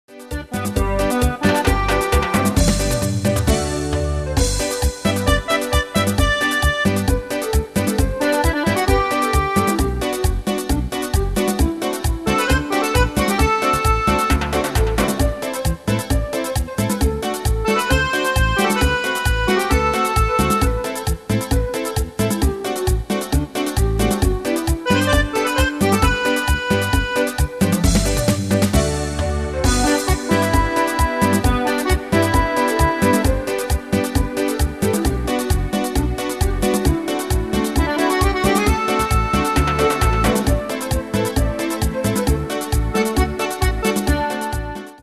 Latino - Bolero MIDI Files Backing Tracks.